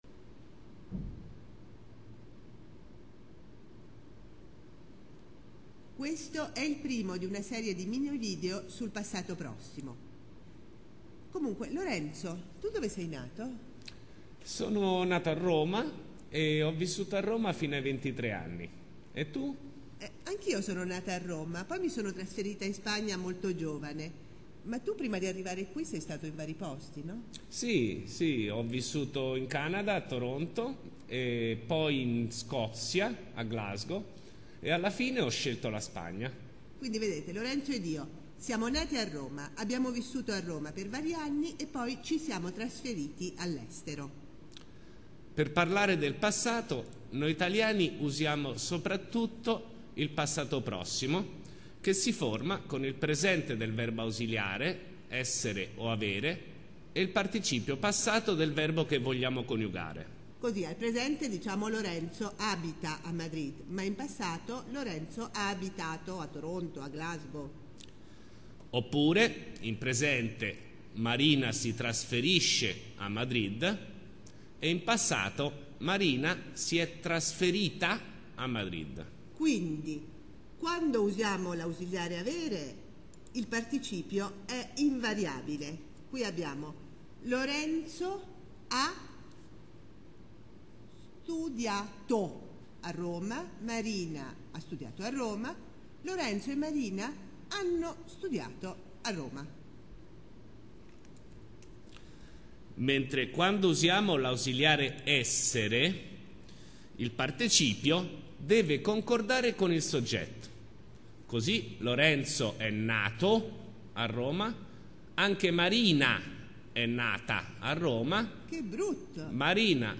Video Clase